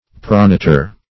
Search Result for " pronator" : Wordnet 3.0 NOUN (1) 1. a muscle that produces or assists in pronation ; The Collaborative International Dictionary of English v.0.48: Pronator \Pro*na"tor\, n. [NL.]